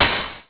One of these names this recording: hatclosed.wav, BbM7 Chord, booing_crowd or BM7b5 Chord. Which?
hatclosed.wav